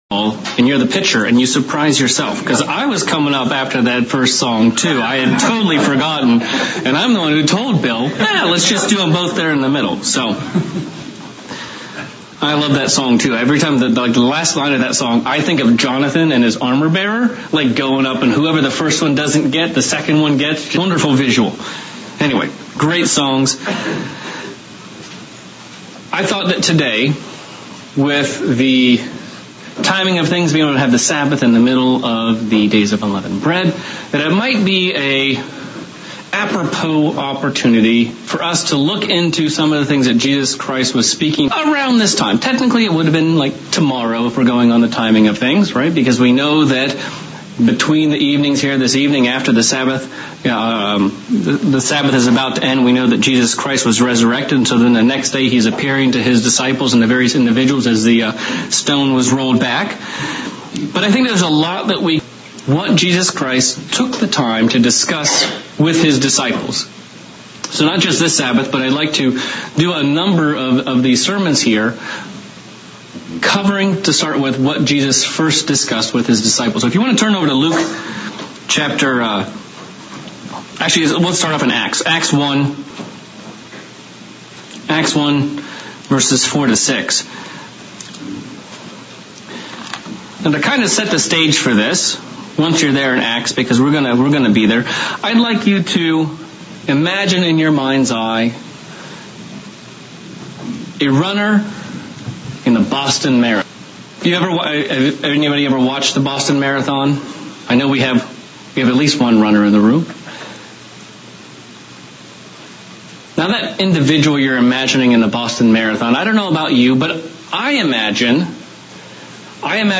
Sermon looking at the subject of the Kingdom of God and our ideas and thoughts about it. What would it take to changes these thoughts towards what God says the KOG truly is.